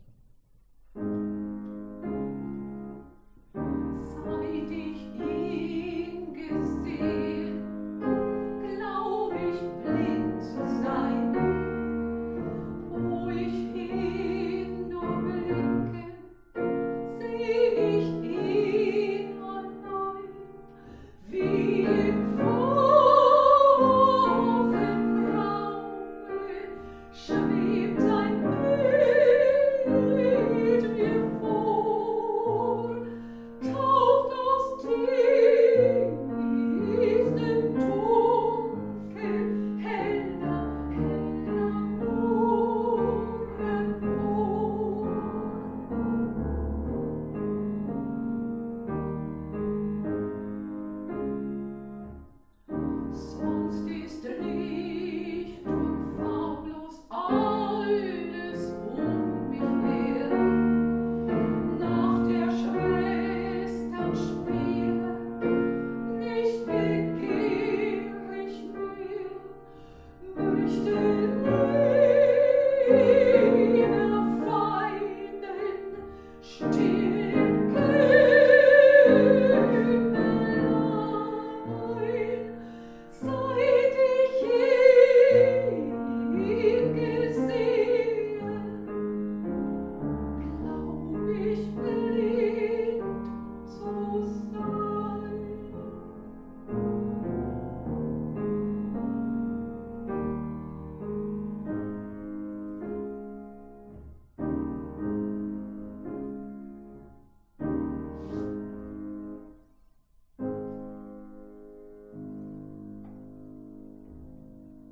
am Klavier